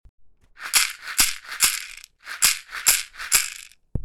ひょうたん底 バスケットマラカス　アフリカ 民族楽器 （n121-17）
ブルキナファソで作られたバスケット素材の素朴なマラカスです。
水草とひょうたんと木の実でできています。
やさしいナチュラルな乾いた音を出します。
この楽器のサンプル音